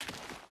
Dirt Walk 4.ogg